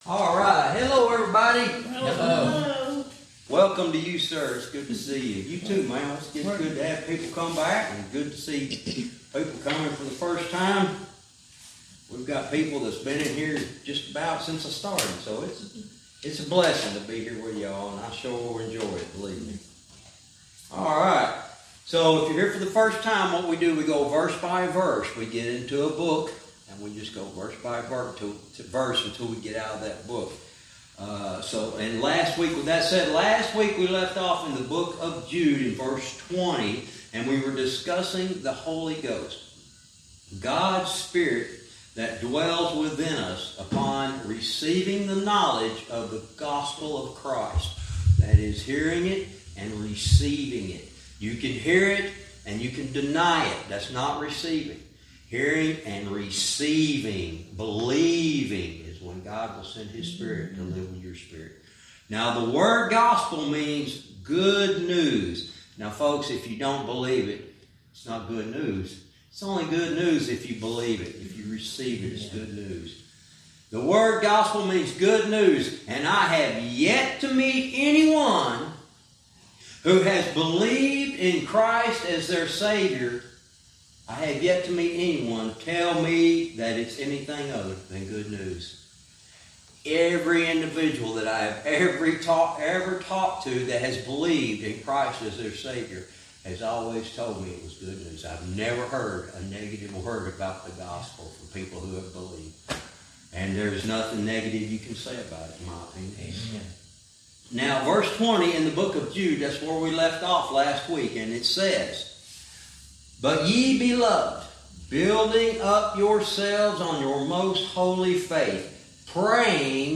Verse by verse teaching - Jude lesson 94 verse 20-21